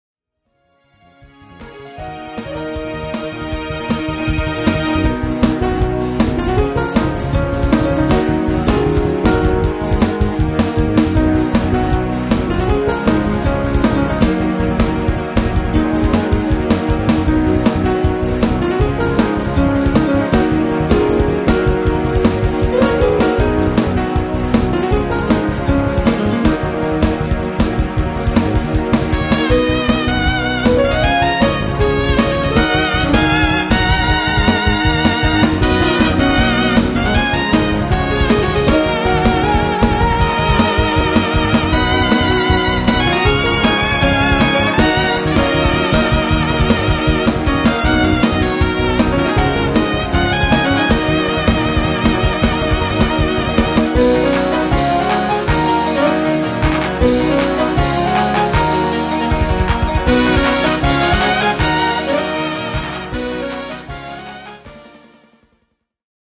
妖精をテーマに作曲しました。